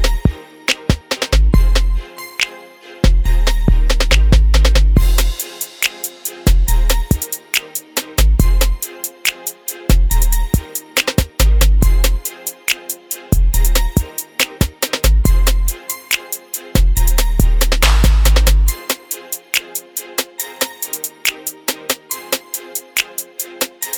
no Backing Vocals R'n'B / Hip Hop 3:01 Buy £1.50